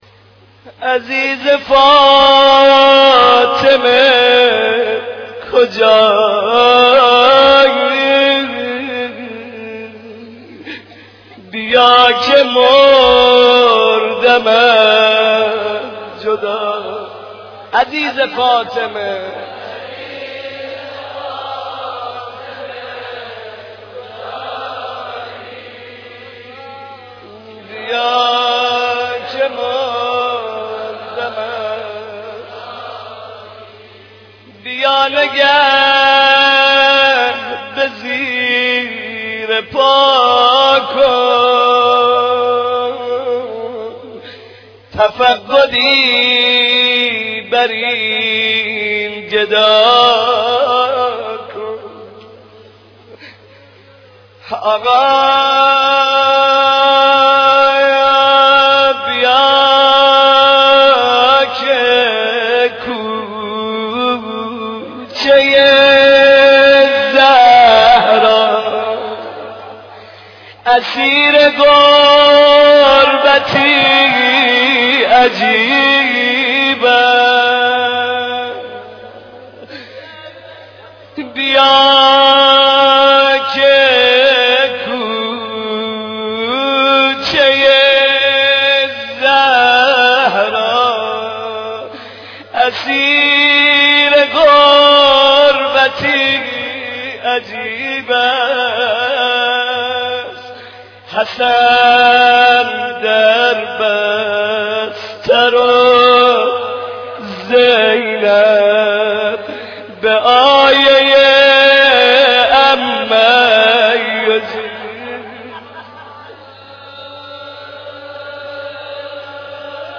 روضه ي امام حسن مجتبي(ع)